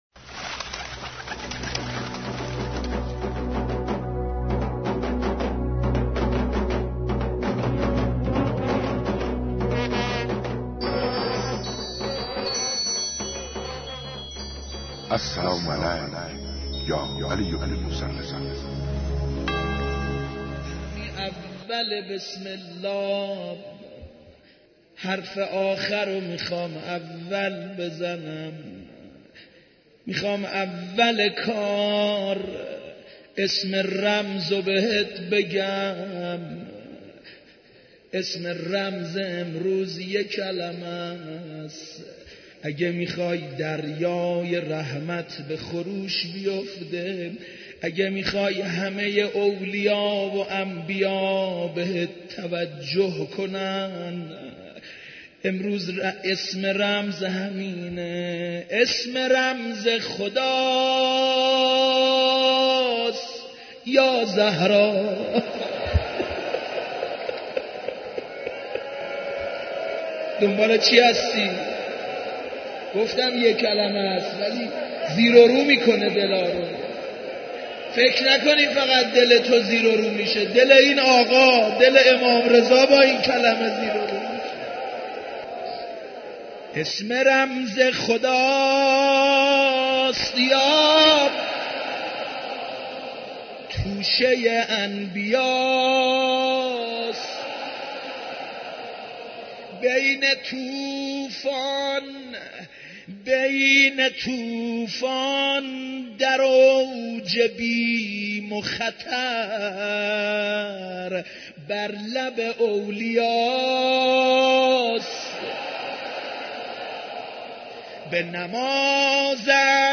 جایگاه و ویژگی ممتاز «عرفه» چیست؟/ در عرفه در حق دیگران هم دعا کنیم/ صوت: قرائت دعای عرفه، روضه خوانی و سینه زنی